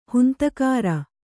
♪ huntakāra